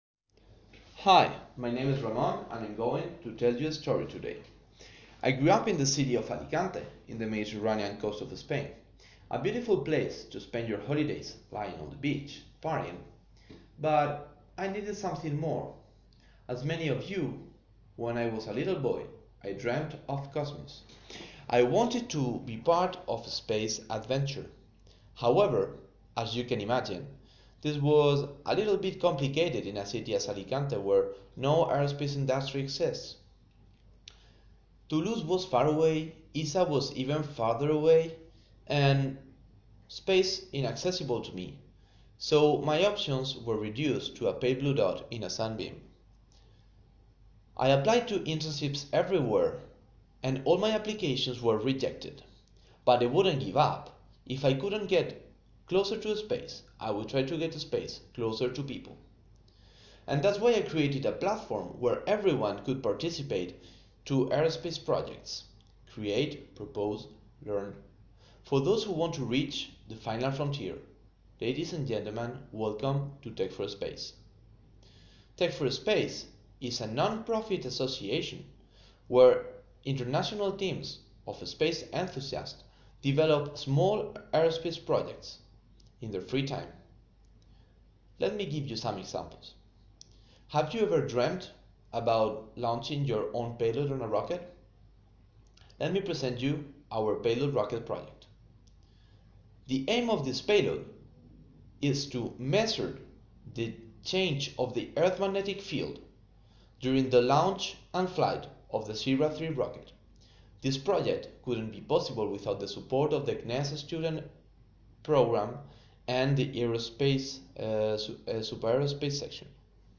Here i let you one of my simulations that I recorded (I think it was the 6th) which was almost what I was looking for.
Test T-5, repetition number 6